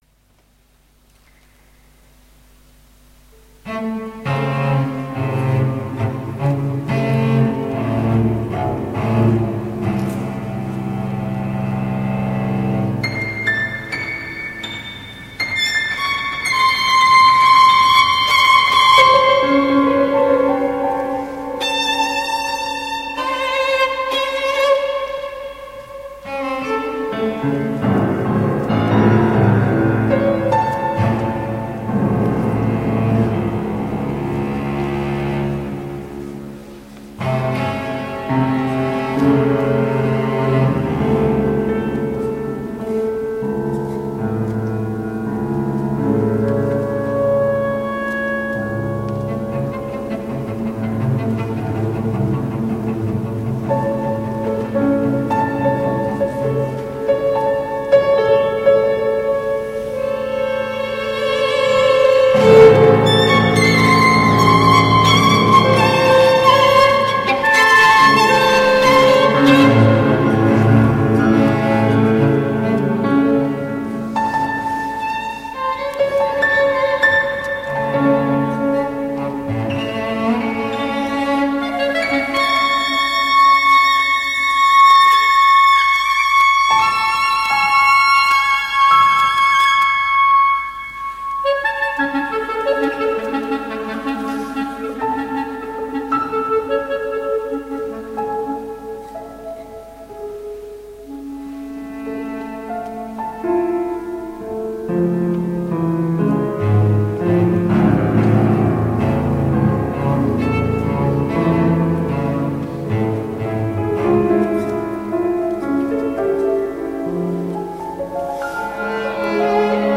(2004) per violino, clarinetto, violoncello e pianoforte